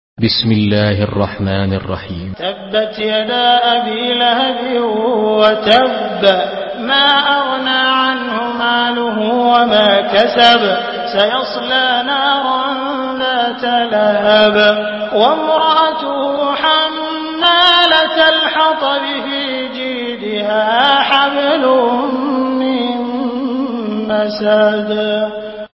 Surah Al-Masad MP3 in the Voice of Abdul Rahman Al Sudais in Hafs Narration
Murattal Hafs An Asim